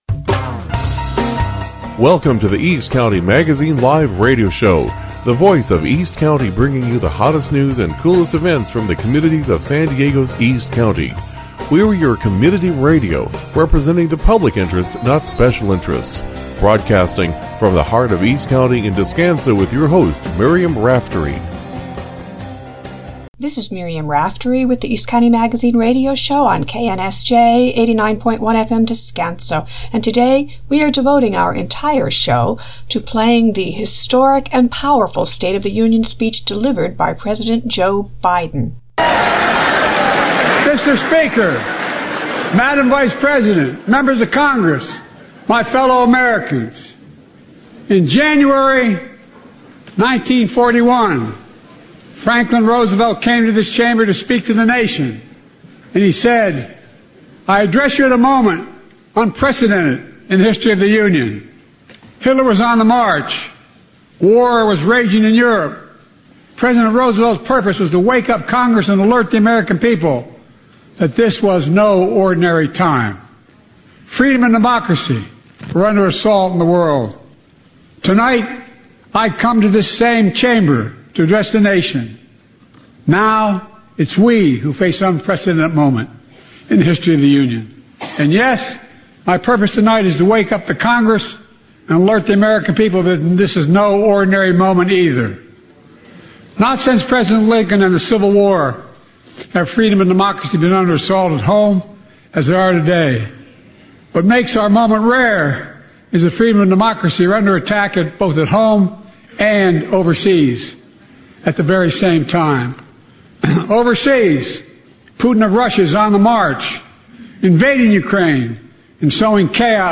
Shows also include station IDs and public service announcements.